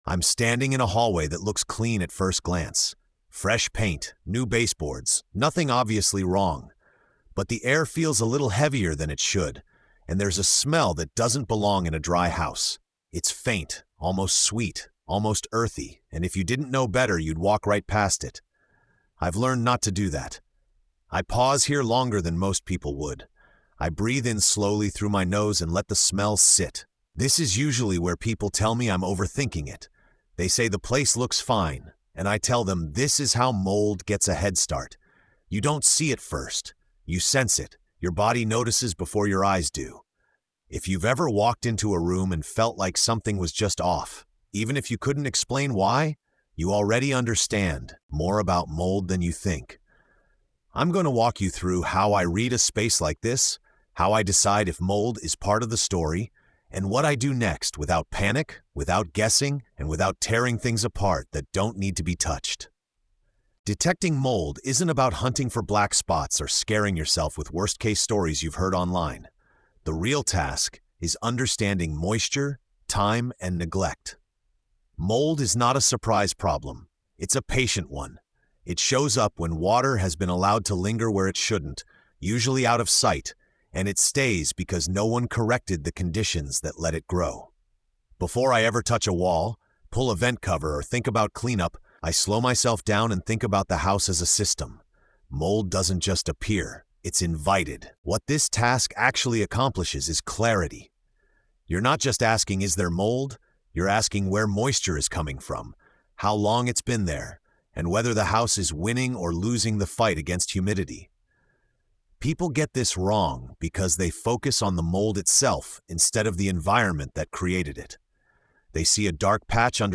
With calm authority and real job-site wisdom, the narrator shows how to slow down, interpret what a house is telling you, and decide what truly needs to be done—and what doesn’t. The tone is steady, grounded, and empowering, replacing panic with judgment and confidence.